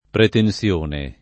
pretenSL1ne] s. f. — talvolta pretenzione [pretenZL1ne], spec. nel sign. di «ambizione vanitosa»: mantelli e scialli alla buona, senza pretenzione [mant$lli e šš#lli alla bU0na, S$nZa pretenZL1ne] (Palazzeschi) — più regolare con -s-, conforme al lat. praetensio -onis; alterato poi in -z- sul modello del fr. prétention e dei nuovi valori da questo assunti nel ’700 di pari passo con prétentieux (it. pretenzioso)